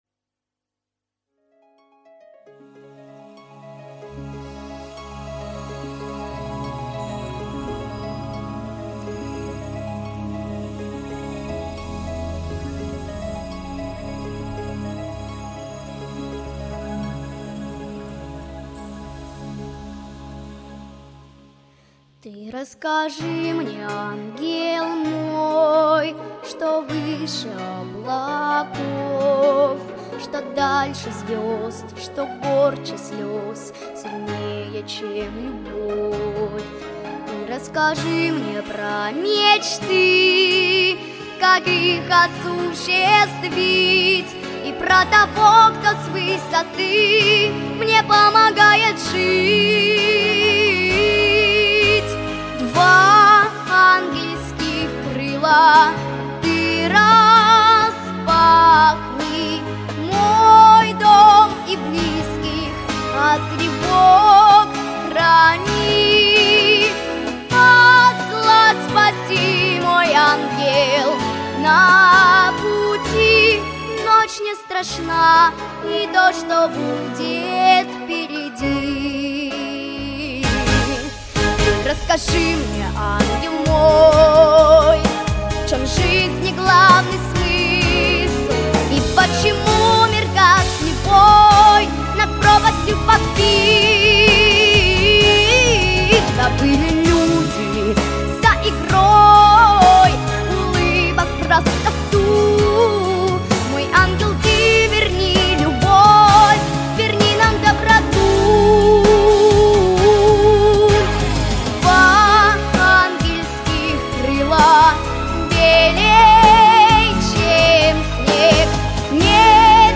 • Категория: Детские песни
христианские песни